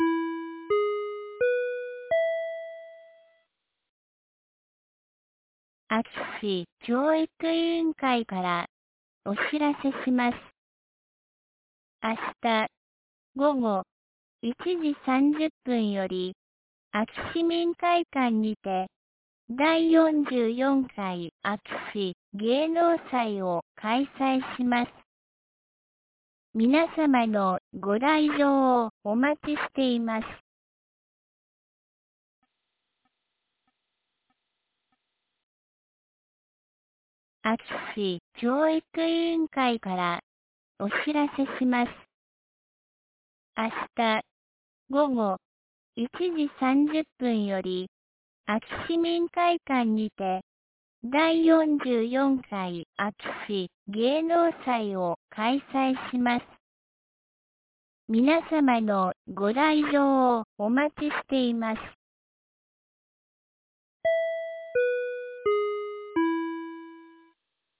2023年10月14日 16時01分に、安芸市より全地区へ放送がありました。